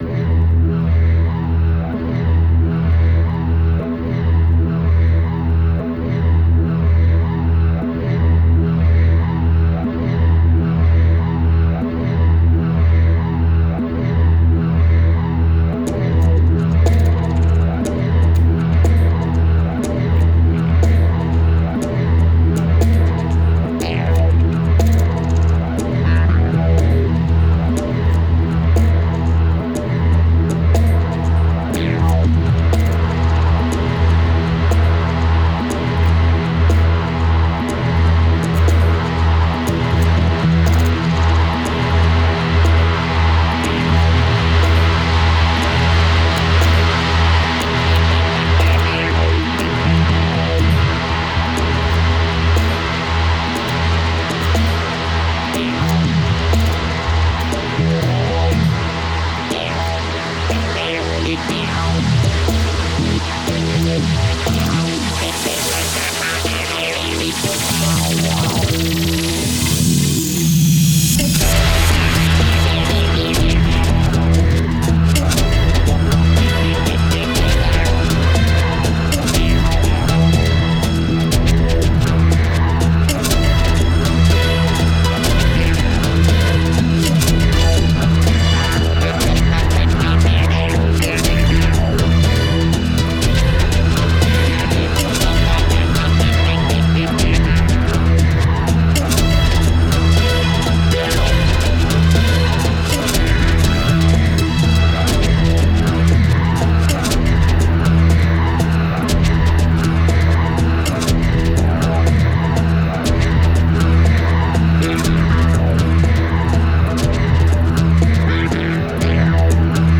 Ierakstīts 2008. gada decembrī Pārdaugavā.